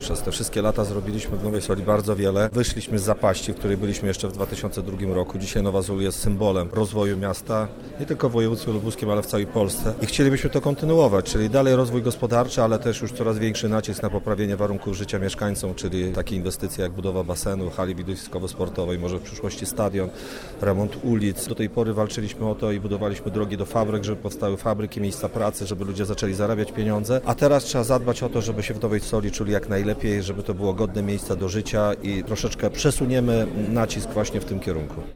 W Nowej Soli odbyła się inauguracyjna sesja rady miasta. Radni złożyli ślubowanie, a prezydent Wadim Tyszkiewicz przysięgę.